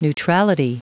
Prononciation du mot neutrality en anglais (fichier audio)